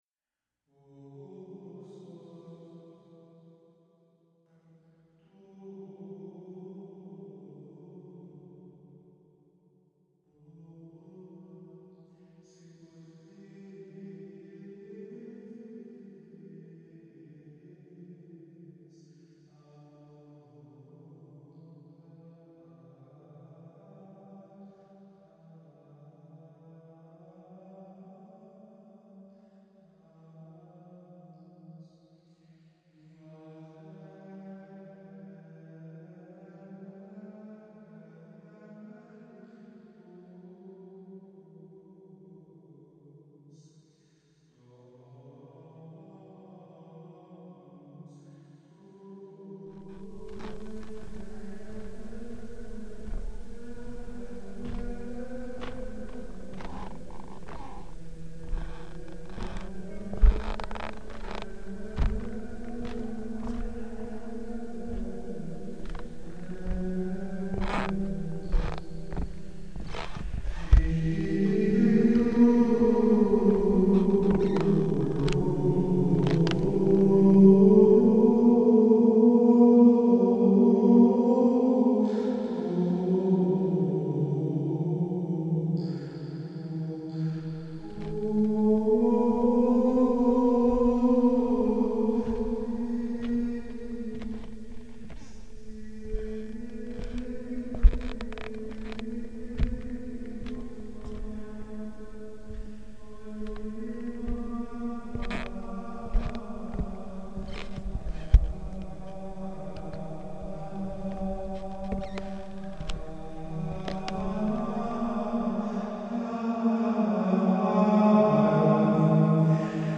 Composición sonora realizada sobre una pieza de monjes benedictos y pisadas grabas en el piso del Teatro de la Ciudad Emilio Rabasa.